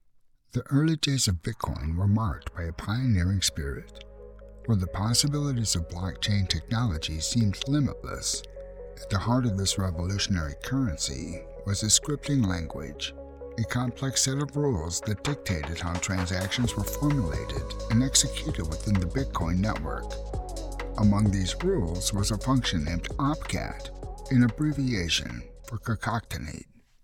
Meet a voice that captivates and connects—deep, gritty, and authentic.
Bitcoin- Conversational, Articulate, Informative, Sincere, Warm, Sincere, Genuine,Gravitas
North American Midwest, Upper Midwest